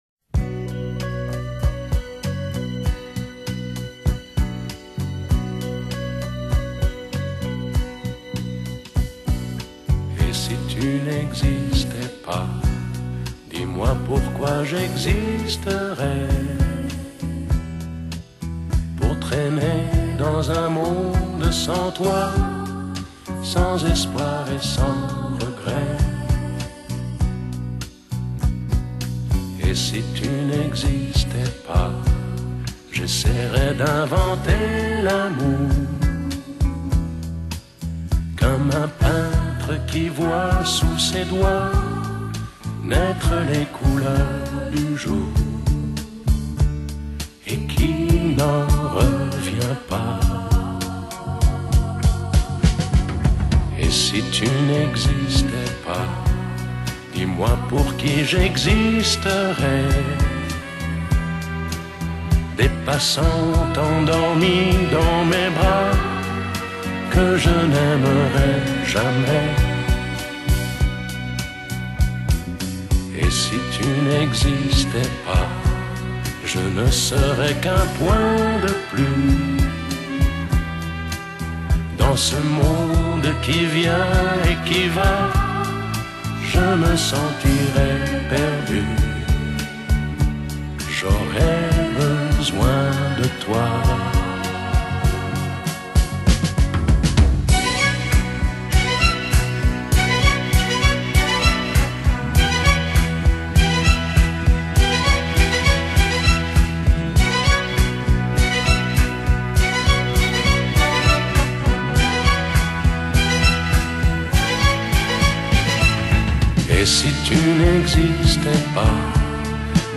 Genre: Chanson, Pop, Easy Listening